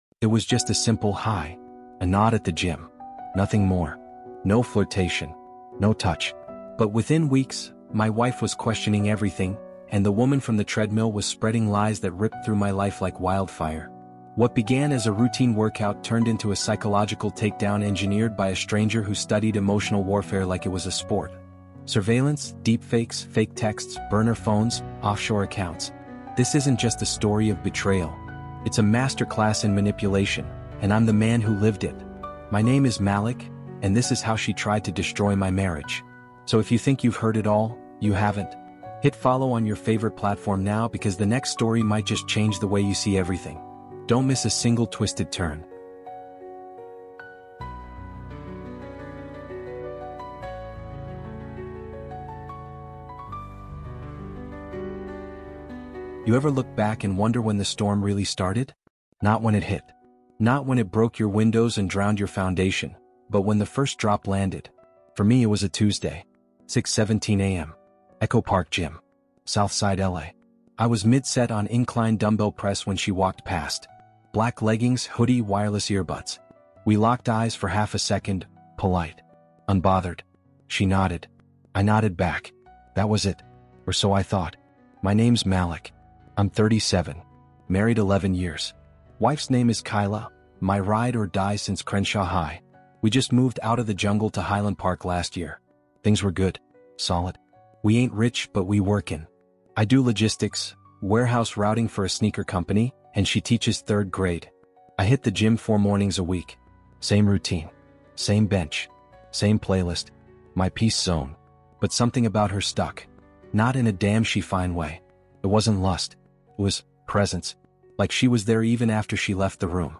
MANIPULATION: The Stranger at the Gym Who Tried to Destroy My Marriage is a gripping true crime-inspired psychological thriller told through a raw first-person lens. Set in the gritty shadows of South L.A., this immersive audio story unpacks the chilling world of manipulation, influence, and emotional control.